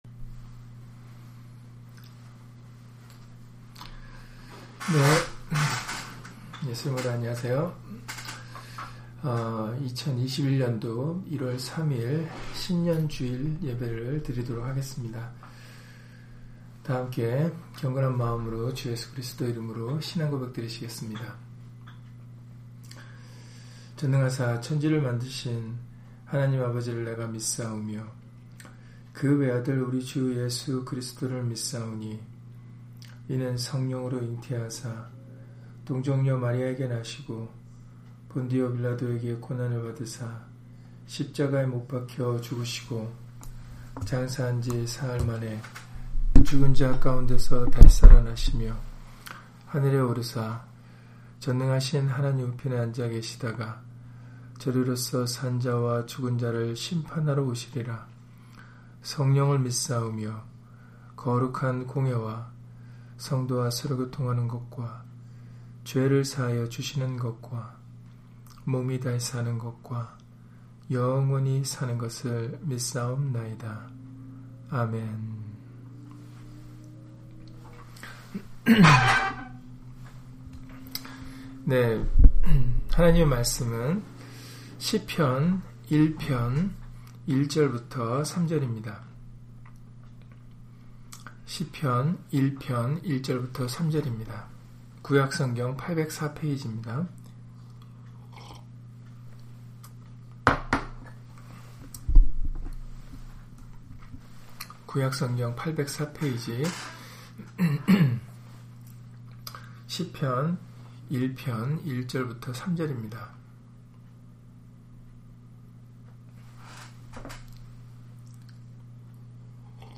시편 1편 1-3절 [신년 주일] - 주일/수요예배 설교 - 주 예수 그리스도 이름 예배당